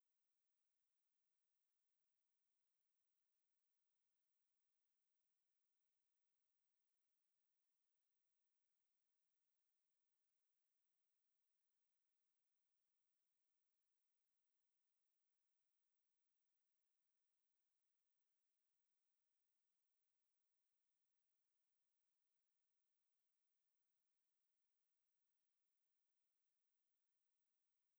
-Celestion Speaker 75
-SM57
Everything was recorded with the scarlet solo.
Ultimate Chorus Speaker Edge.mp3